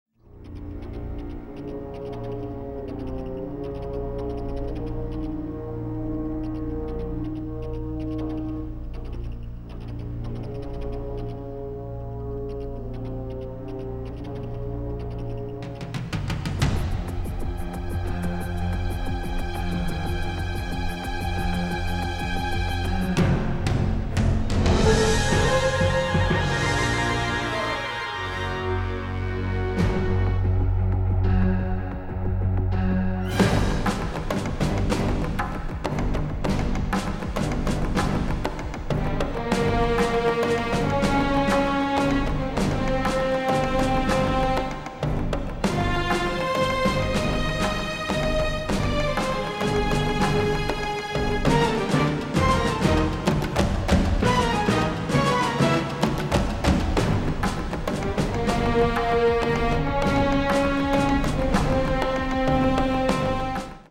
primarily synths
strings, winds, and heavy percussion